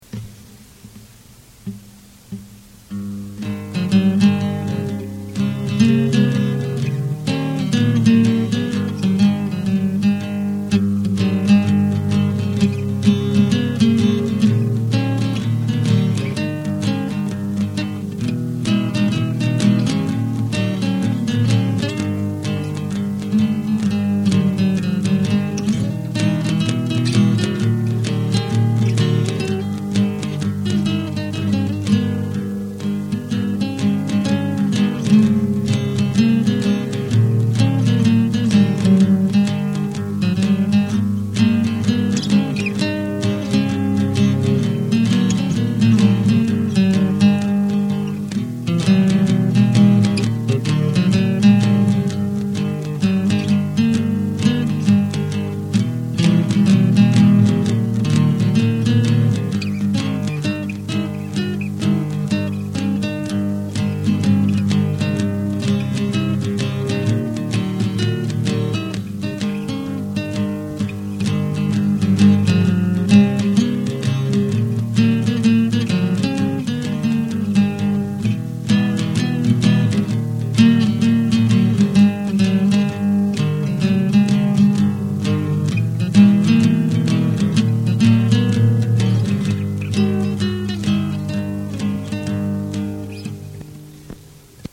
J' ai acheté en 1986 un petit studio 4 pistes à cassette (un porta one de chez Tascam pour les techniciens)
Bien sur la qualité sonore n'est pas terrible et la justesse de l'orchestration, l'exécution des morceaux
1'35 Trois guitares folk pour une petite ballade